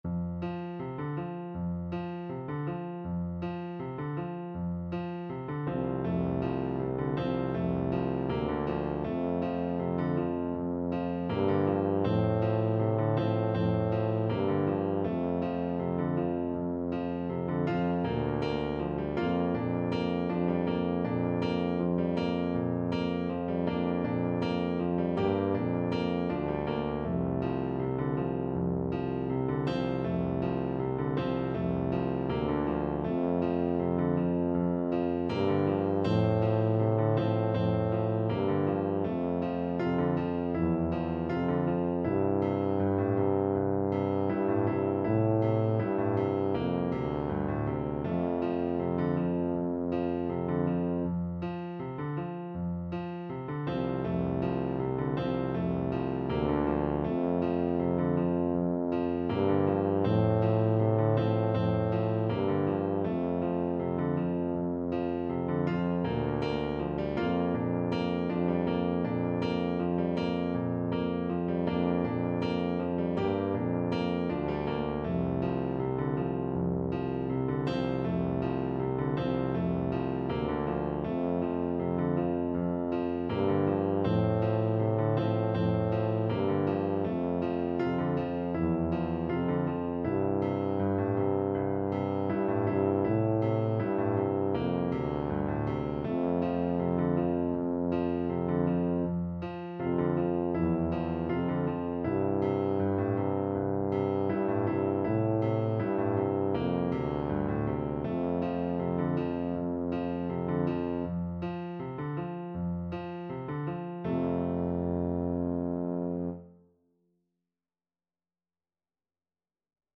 Jazz Trad. Worried Man Blues Tuba version
Tuba
F major (Sounding Pitch) (View more F major Music for Tuba )
4/4 (View more 4/4 Music)
Molto Allegro = c.160 =160 (View more music marked Allegro)
jazz (View more jazz Tuba Music)